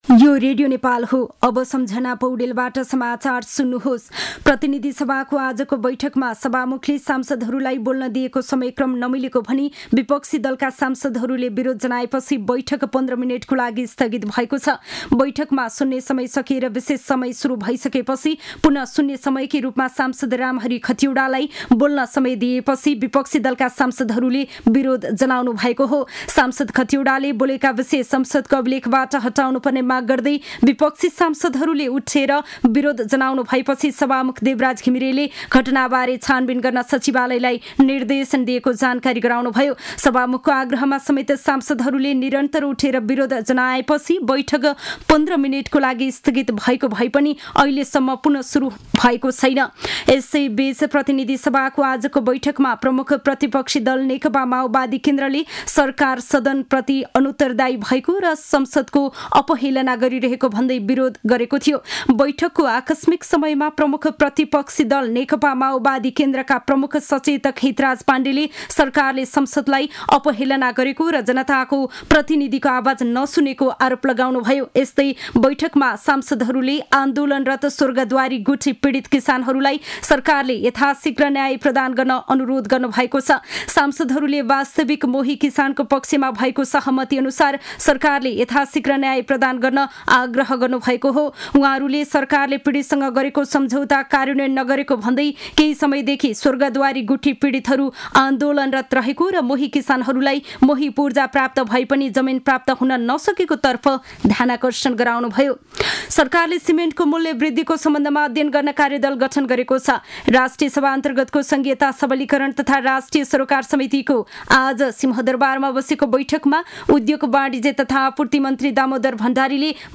दिउँसो ४ बजेको नेपाली समाचार : ३० माघ , २०८१